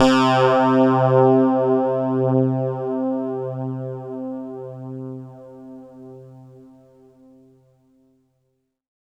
12 PULSATN-R.wav